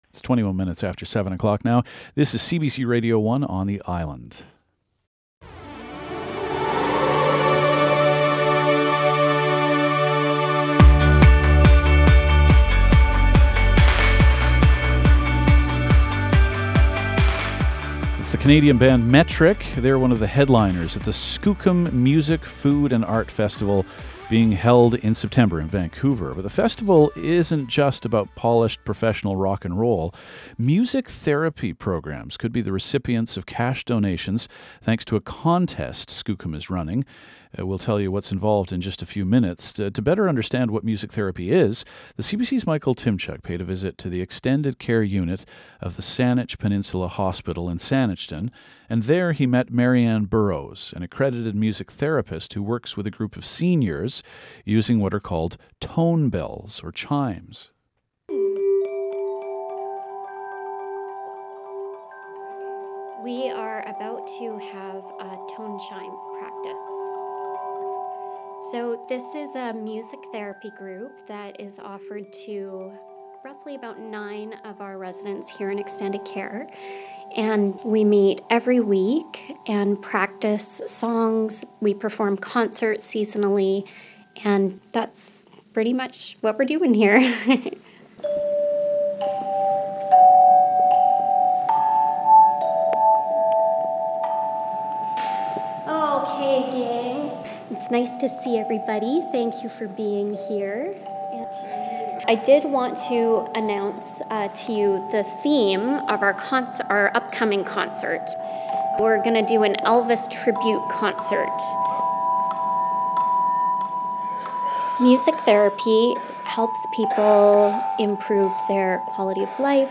Listen to our interview with CBC Radio, June 7/18